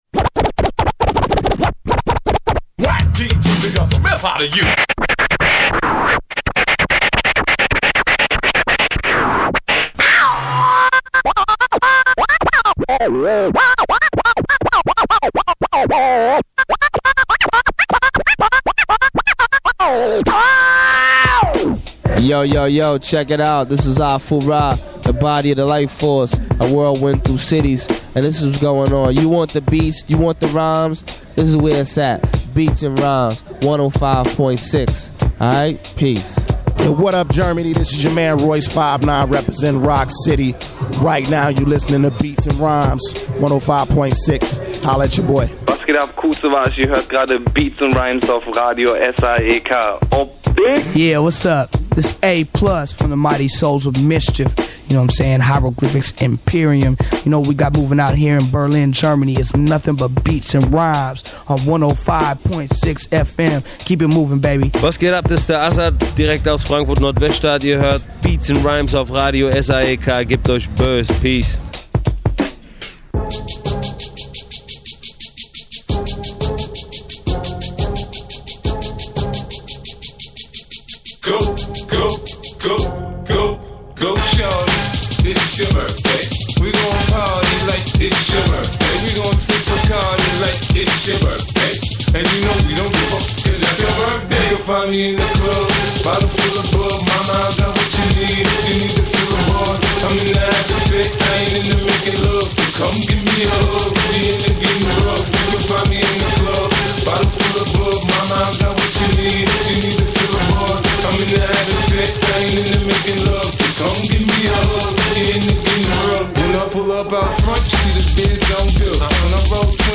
Inhalte: VA Tips, Musikspecial: Hip Hop/Rap meets Drum & Bass